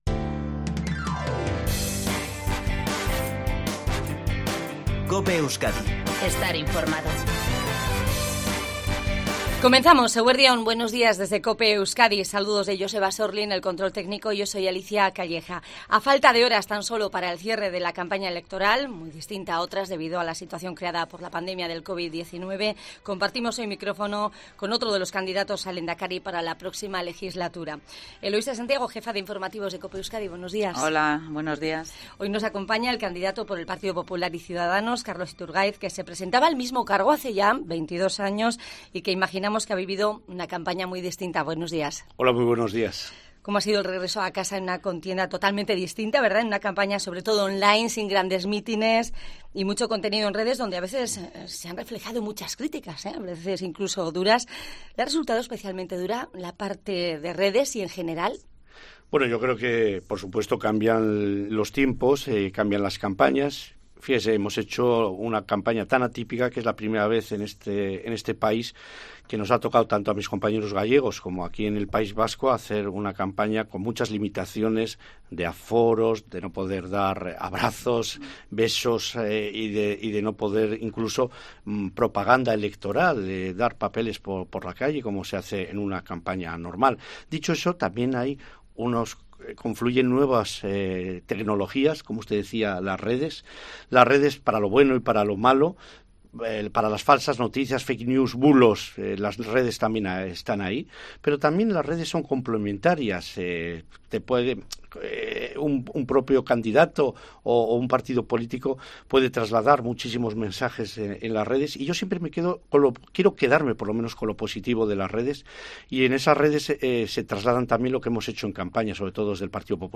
Escucha la entrevista completa a Carlos Iturgaiz en COPE Euskadi